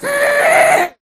Sound / Minecraft / mob / ghast / scream1.ogg
scream1.ogg